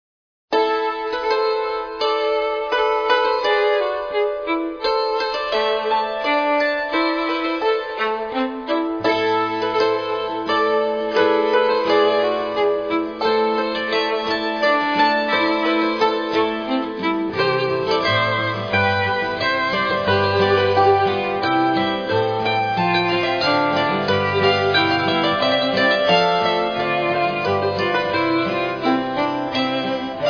Arranged for dulcimer, recorder, violin, guitar and piano